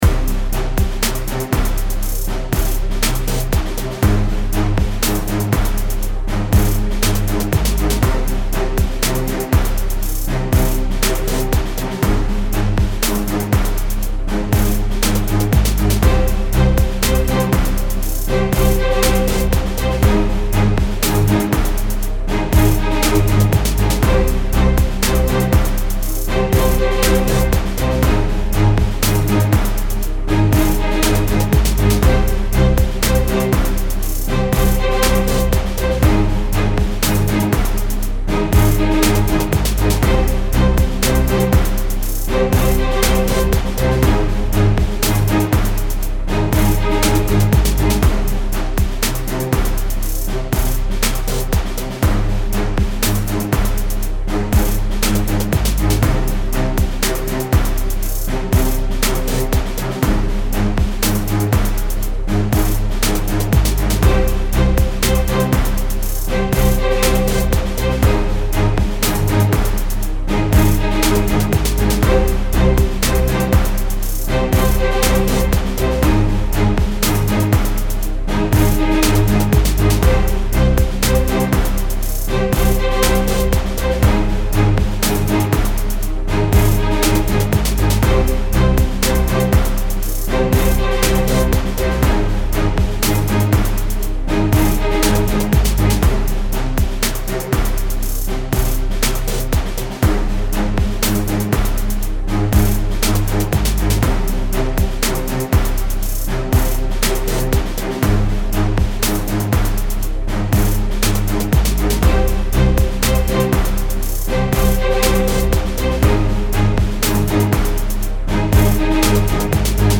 エレクトロニカルなリズムを加えたバージョンです。